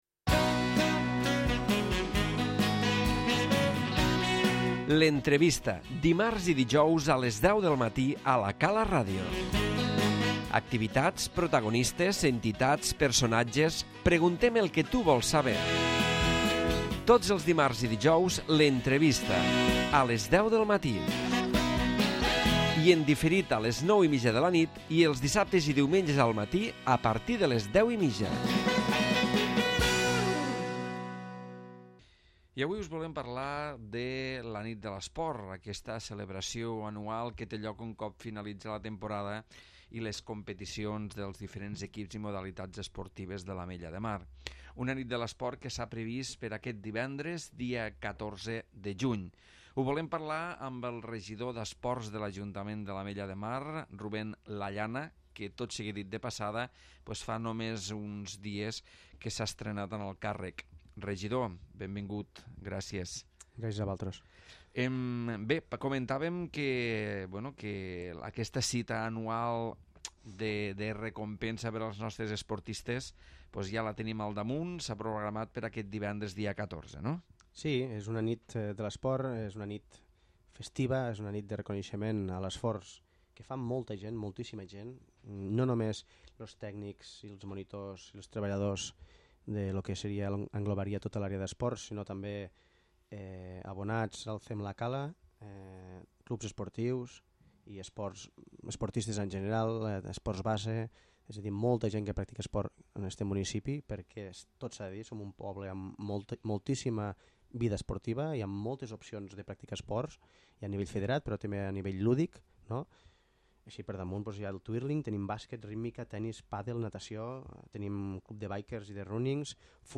L'Entrevista
Avui a l'entrevista hem parlat amb Rubén Lallana, regidor d'Esports, sobre la Nit de l'Esport i les activitats esportives per aquest estiu al CEM la Cala.